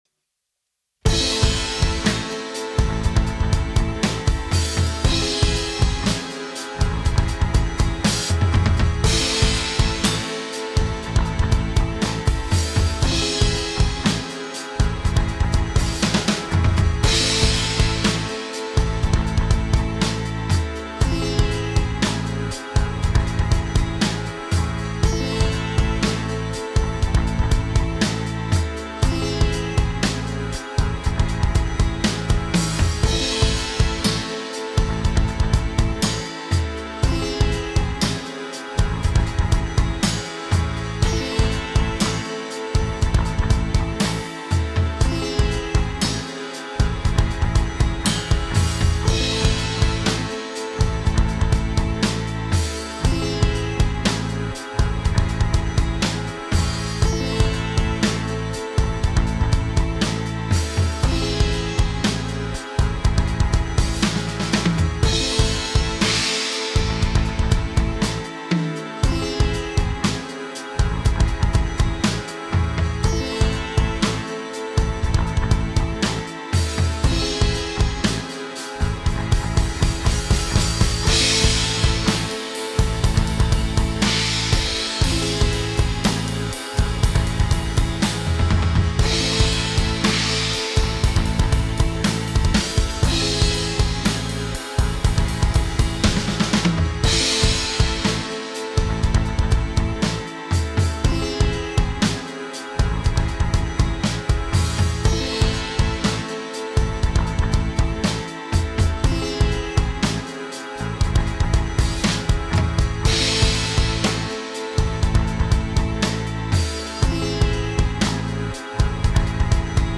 download Harmonic Minor Practice Track